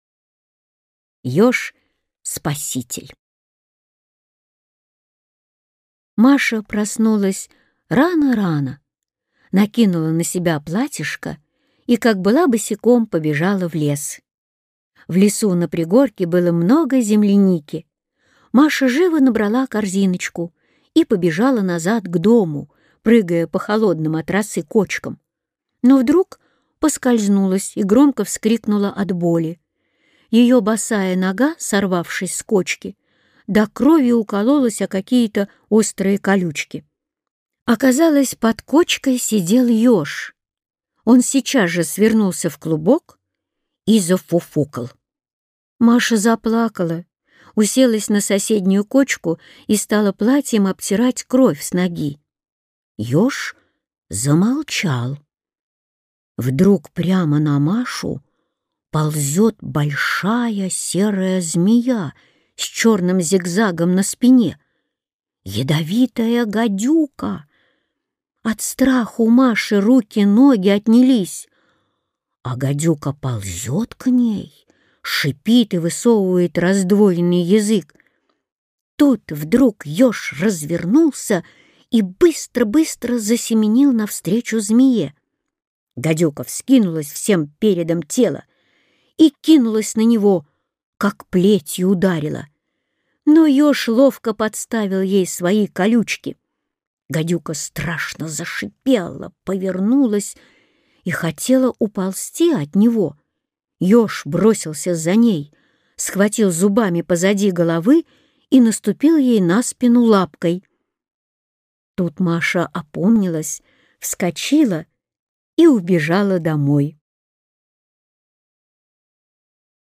Аудиорассказ «Еж-спаситель»